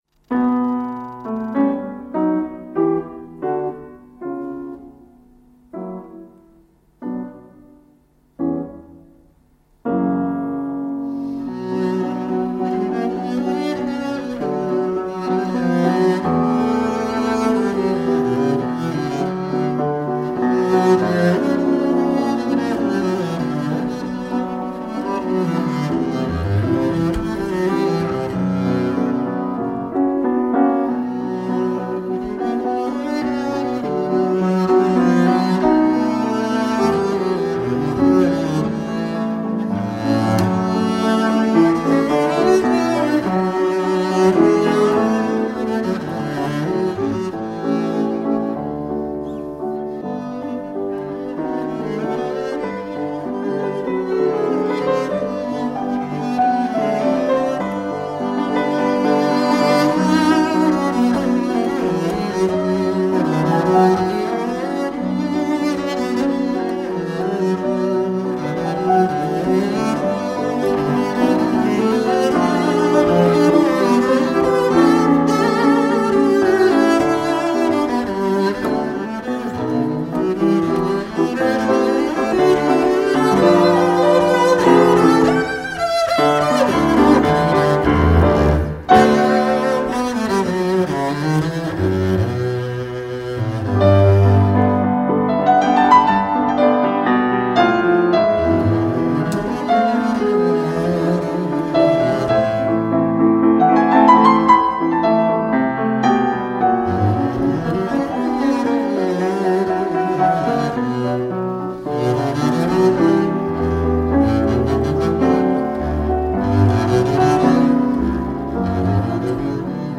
Double bass sonata
Double bass sonata ~1920 (Classical, Romantic) Group: Sonata A sonata written for double bass and piano, though other instrumentations are used, such as solo double bass. Giovanni Bottesini Concerto for Double Bass No 2 in B Minor
Giovanni Bottesini Concerto for Double Bass No 2 in B Minor.mp3